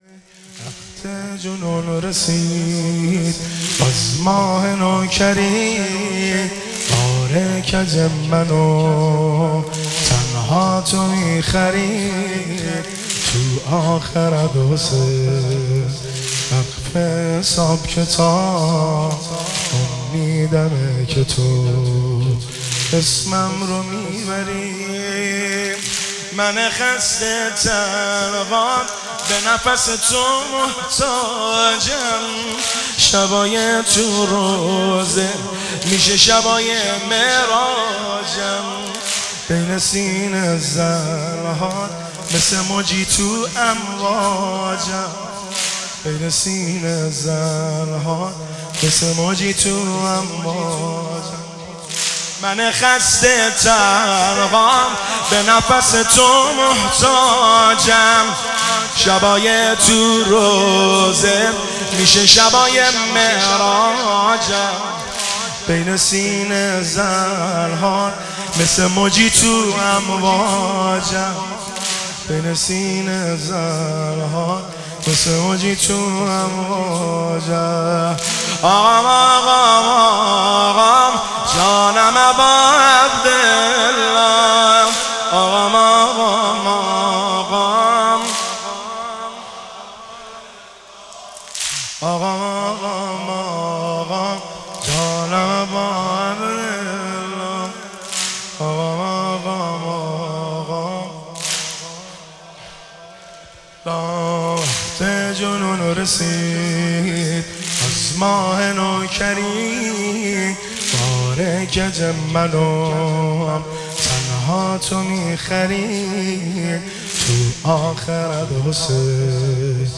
عنوان : واحد تند امام حسین(ع)